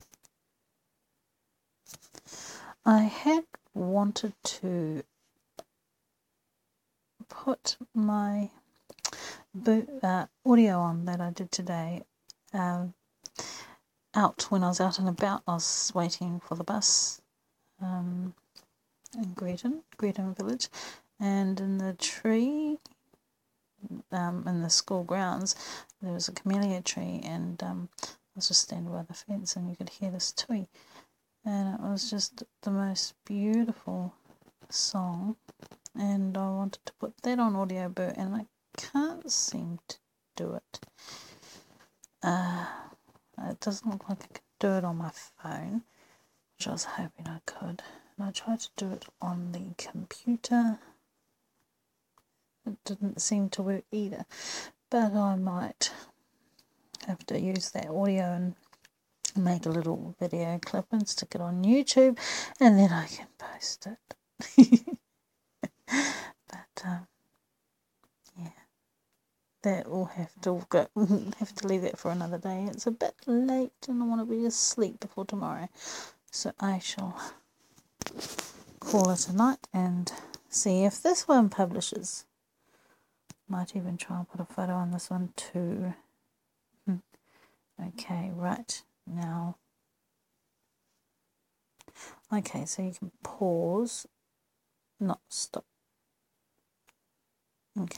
There is a tui is this tree somewhere I haven't located it just yet.